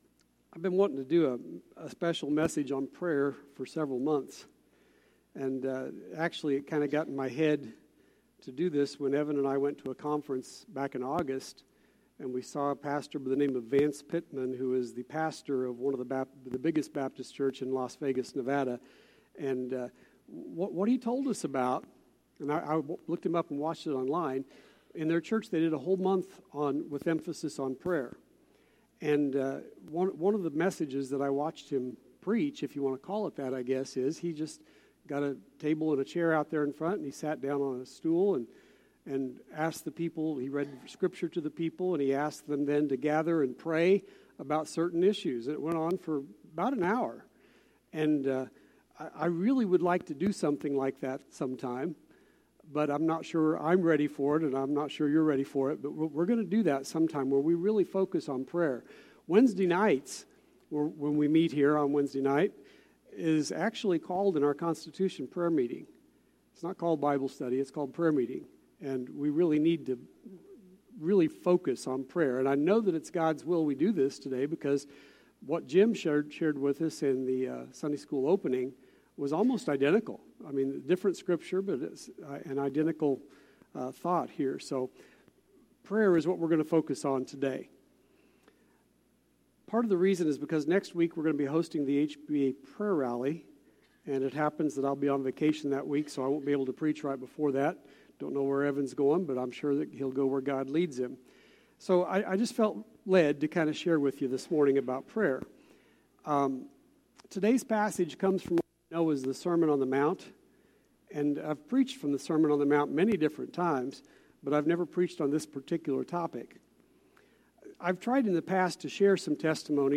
Sermon Archive – Immanuel Baptist Church